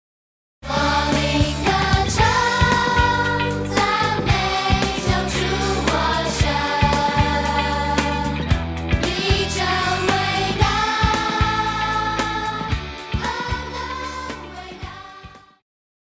Christian
Pop chorus,Children Voice
Band
Hymn,POP,Christian Music
Voice with accompaniment
為了淺顯易懂，除了把現代樂風融入傳統聖詩旋律，針對部份艱澀難懂的歌詞，也稍作修飾，儘可能現代化、口語化；